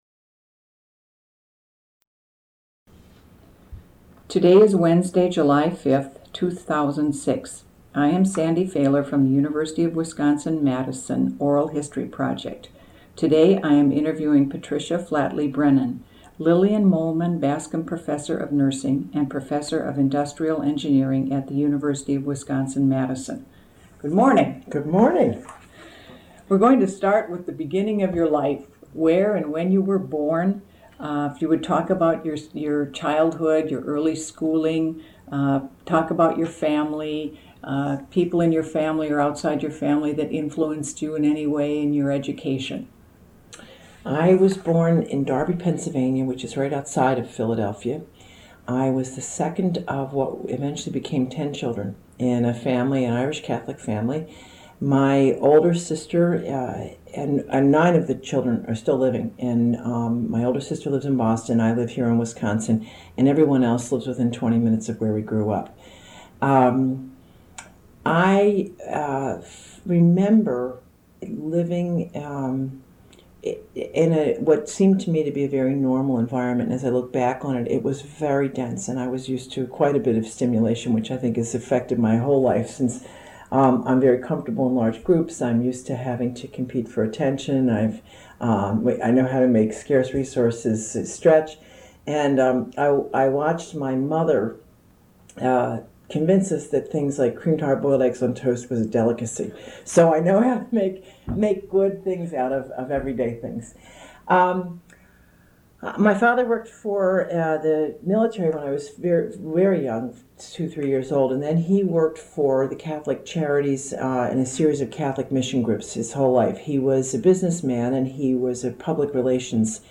Oral History Interview: Patricia Flatley Brennan (0784)